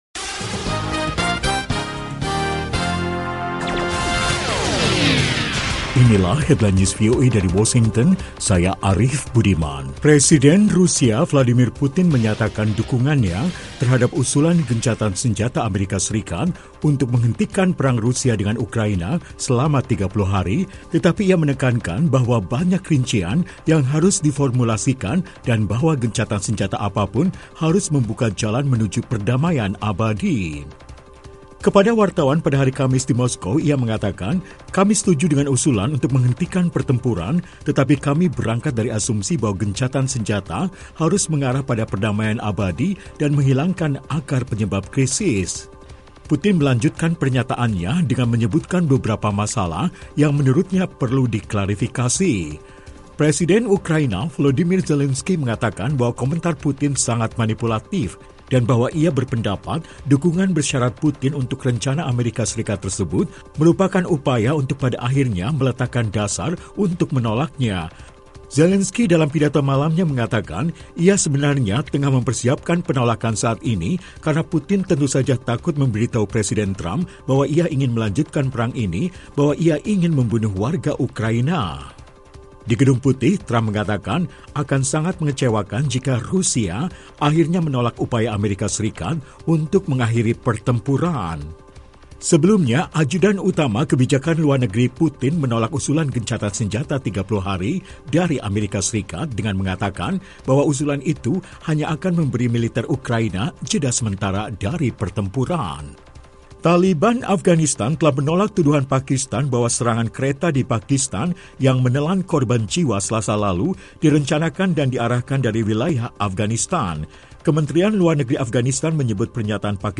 Laporan Radio VOA Indonesia